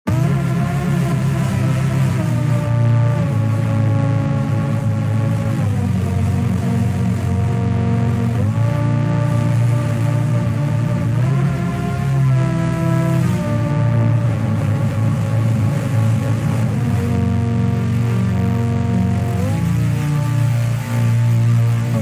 TSP_STRT_87_synth_distortedview_A#min
slingshot-synth-intro.mp3